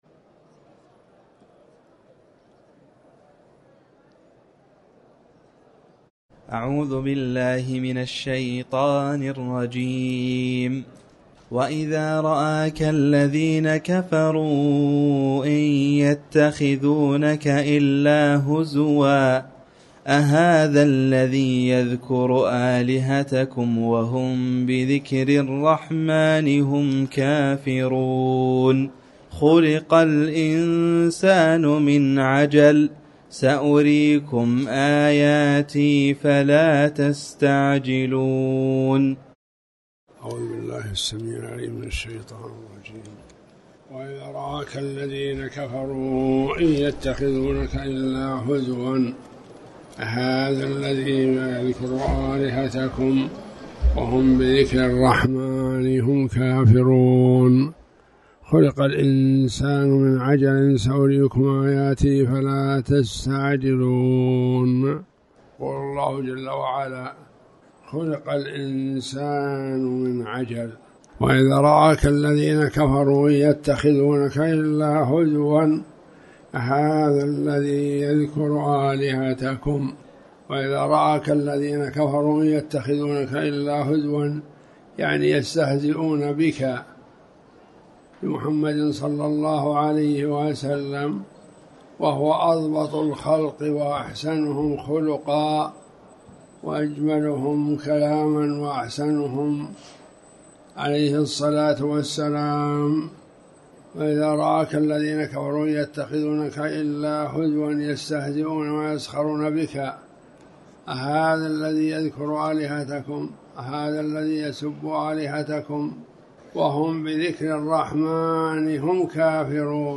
تاريخ النشر ١٤ جمادى الآخرة ١٤٤٠ هـ المكان: المسجد الحرام الشيخ